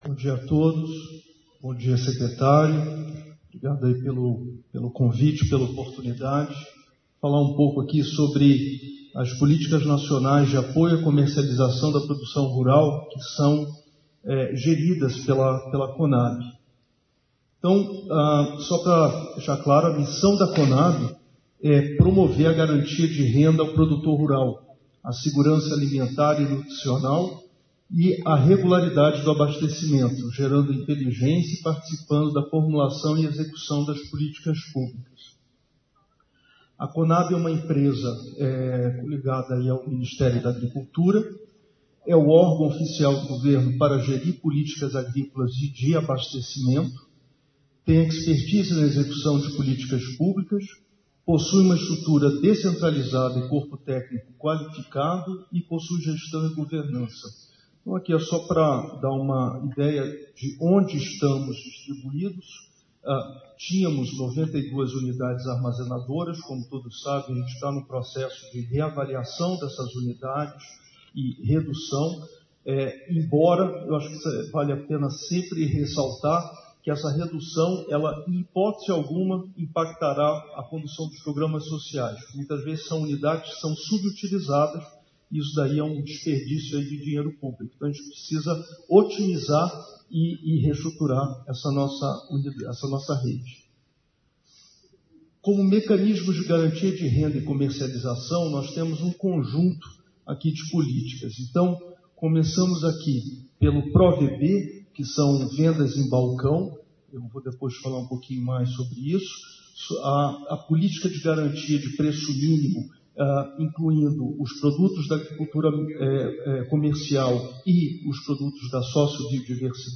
Congresso Brasileiro de Gestores da Agropecuária - José Roberto Carlos Cavalcante – Secretário Nacional de Inclusão Social e Produtiva Rural